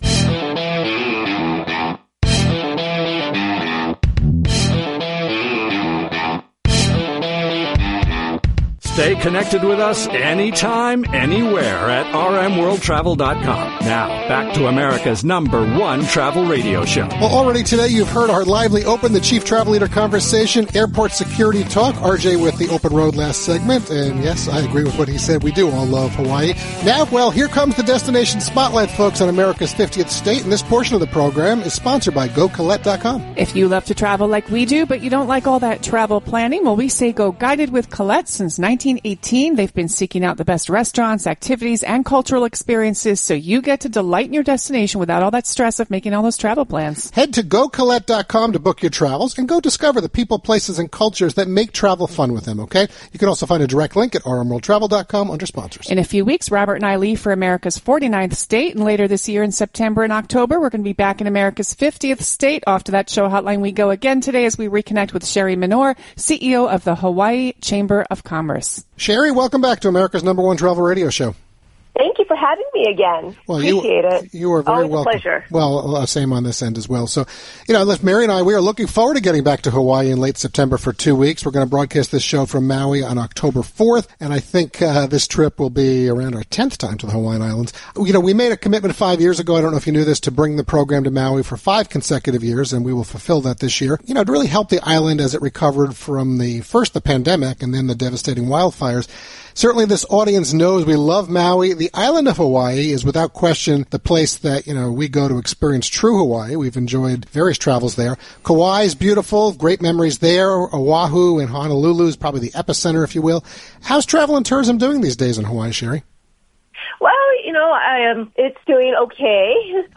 During our live national broadcast of America’s #1 Travel Radio on July 12th — America’s 50th State had our attention.